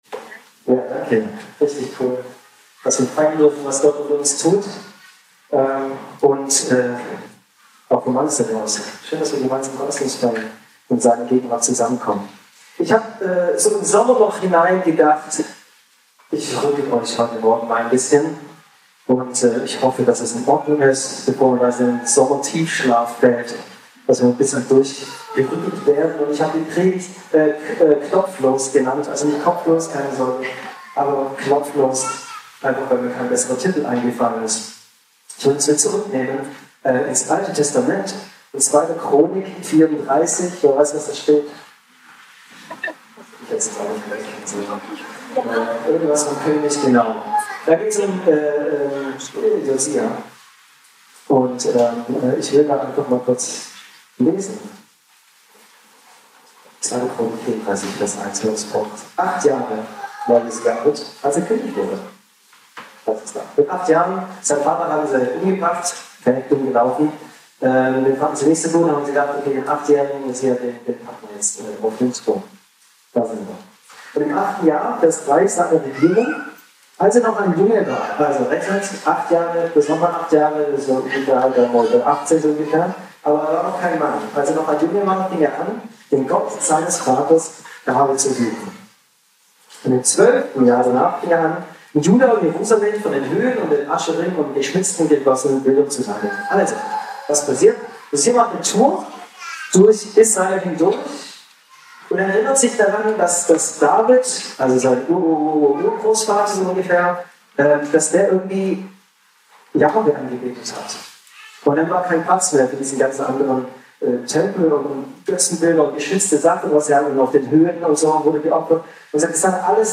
Hinweis: Leider gab es technische Problem bei der Qualität Audioaufnahme.
Dienstart: Predigt